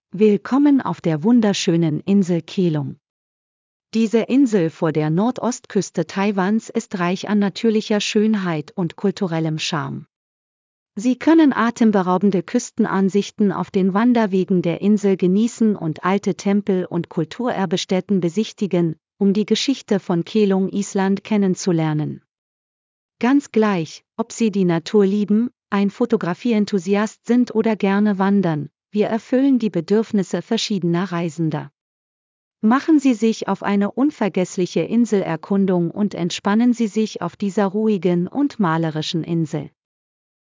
Einminütige kostenlose Probe der Audioführung dieser Strecke